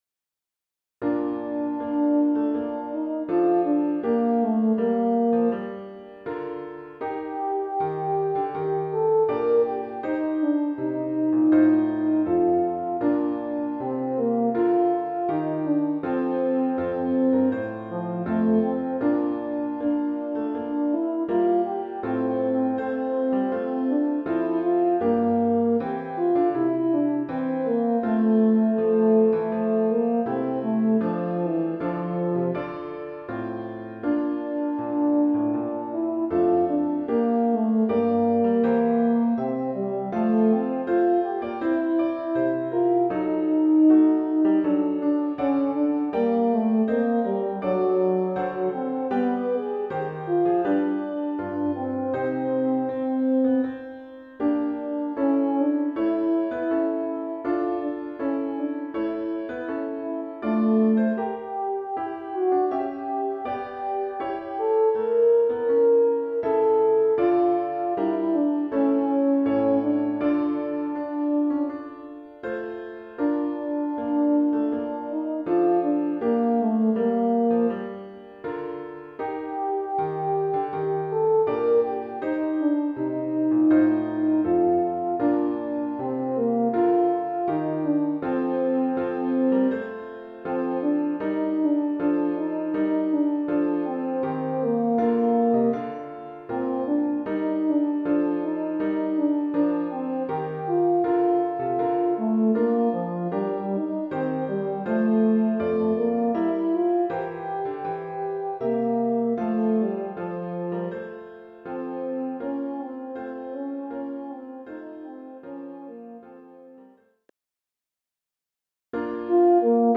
Voicing: EuphoniumSolo